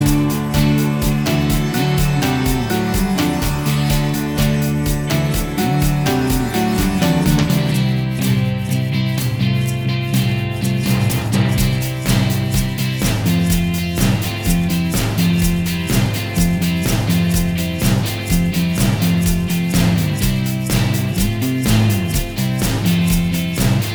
Minus Lead Guitar Rock 3:14 Buy £1.50